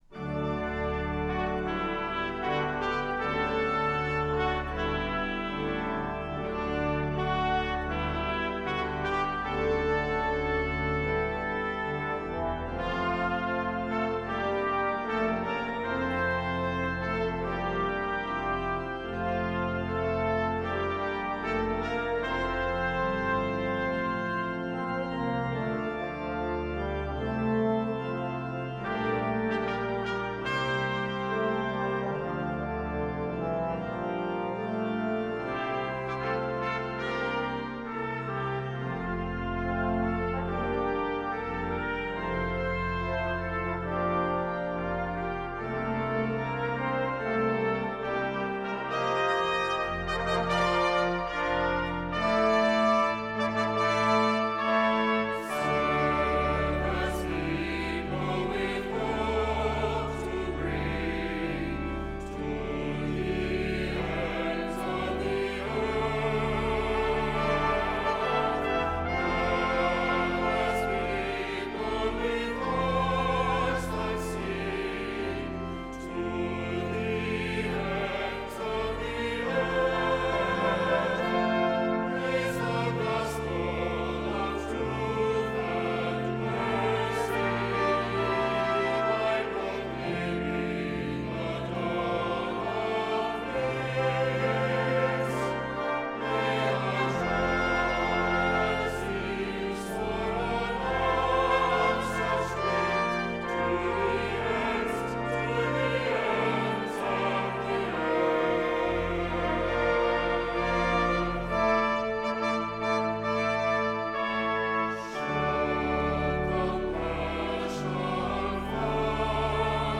Voicing: "SATB","Assembly"